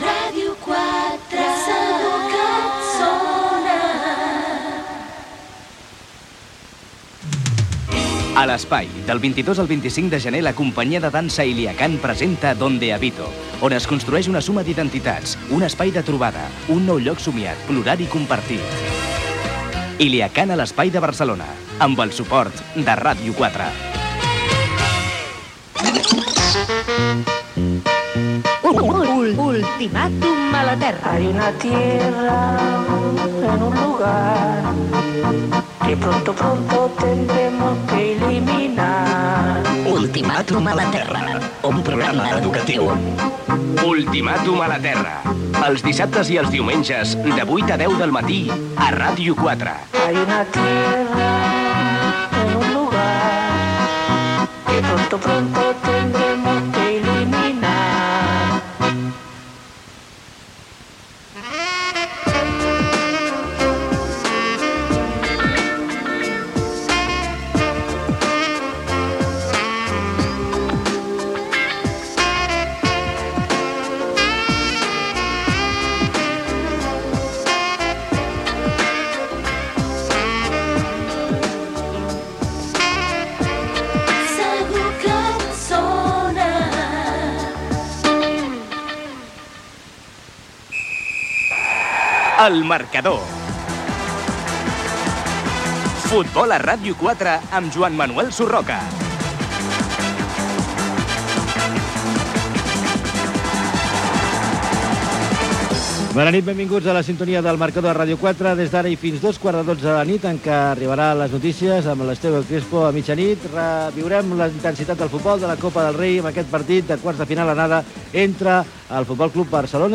Indicatiu de l'emissora, anunci de l'Espai de Barcelona, promo del programa "Ultimàtum a la terra", indicatiu de l'emissora, careta del programa i presentació inicial.
Esportiu
FM